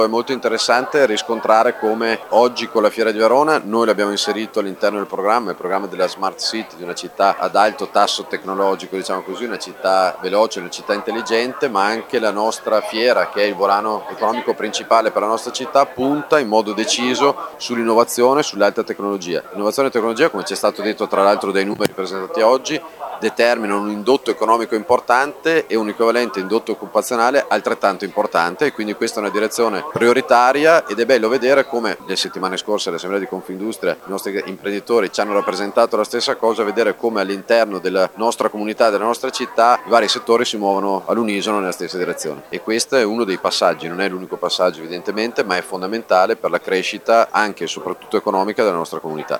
Grande soddisfazione per il nuovo appuntamento che sbarca in città a Verona, è stata espressa anche dal neo eletto Sindaco Federico Sboarina:
Sindaco-Sboarina.mp3